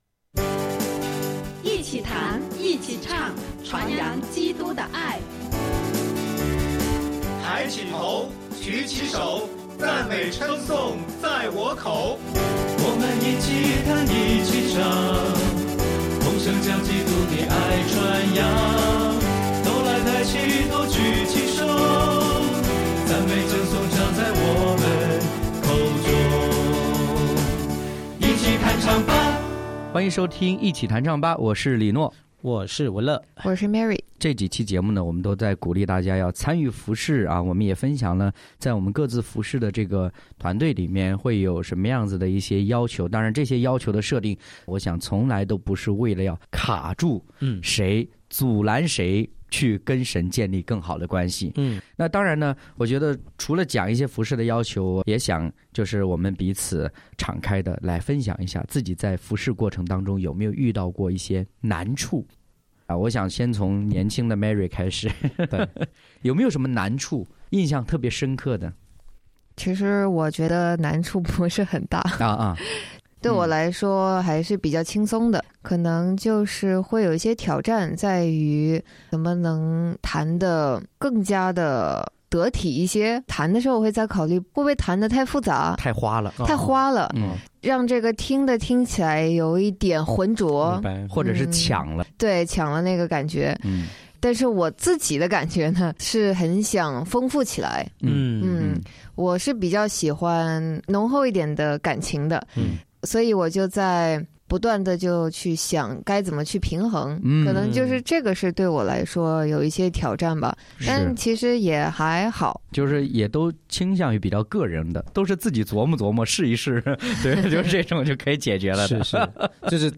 诗歌弹唱，享受赞美